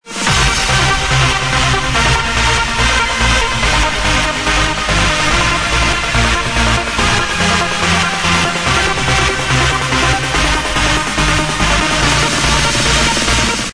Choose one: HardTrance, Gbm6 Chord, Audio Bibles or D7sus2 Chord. HardTrance